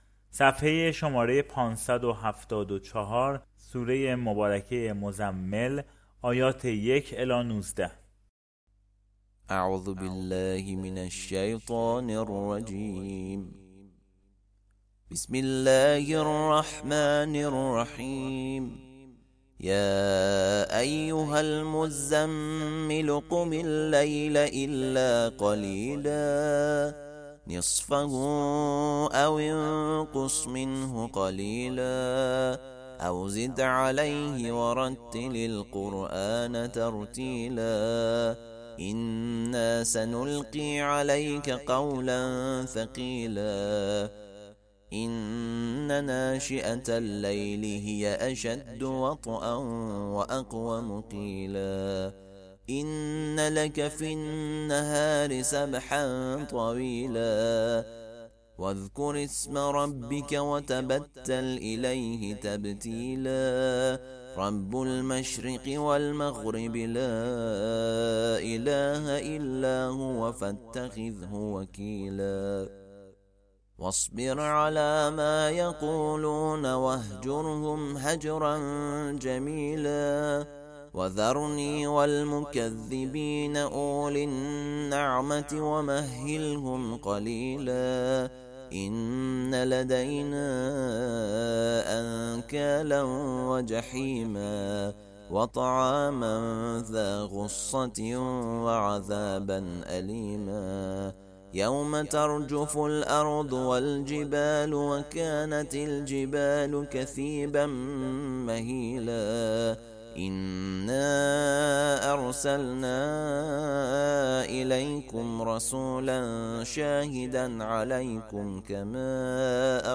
ترتیل صفحه ۵۷۴ از سوره مزمل (جزء بیست و نهم)